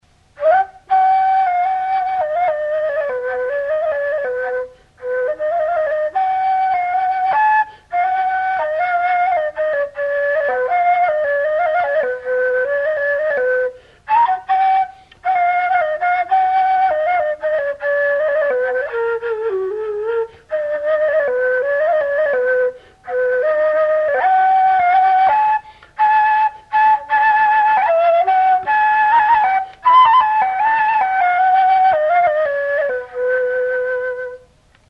NAY.
Zehar-flauta da. 7 zulo ditu (6 aurrekaldean eta 1 atzekaldean).